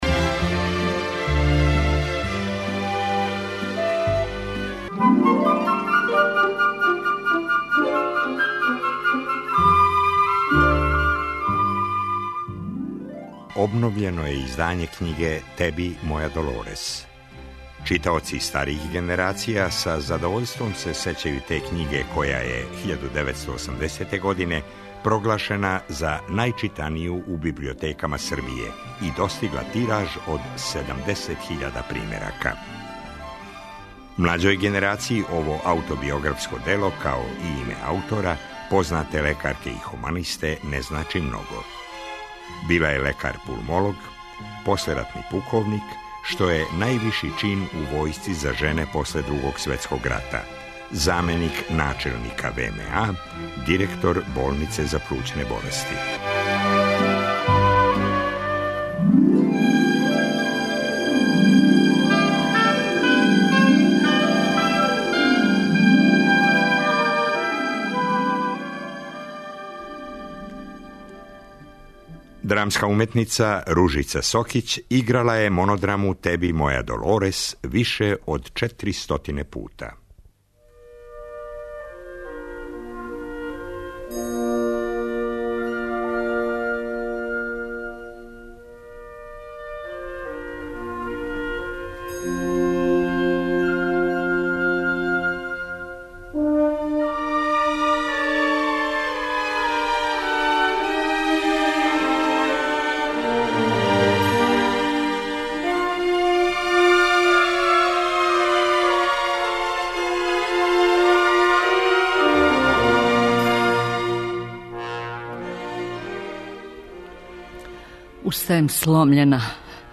Када смо 2012. године обележили сто година од рођења докторке Саше Божовић, упутили смо позив Ружици Сокић да исприча сећања на познату докторку. Радо је прихватила наш позив и том приликом говорила је одломке из романа 'Теби, моја Долорес' Саше Божовић.